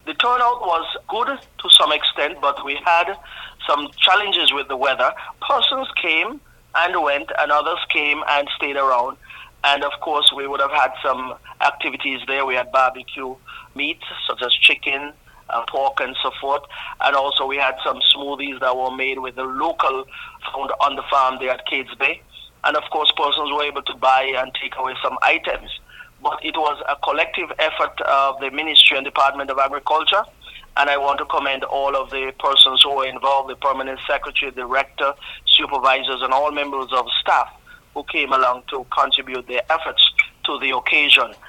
The VONNEWSLINE spoke with Nevis’ Minister of Agriculture, Alexis Jeffers, who gave this feedback on the Fun day: